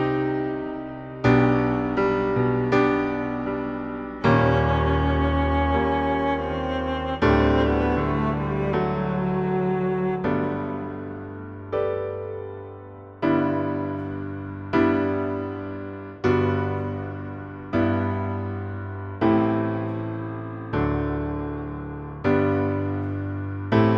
Live In Chile 2009 Pop (1980s) 4:17 Buy £1.50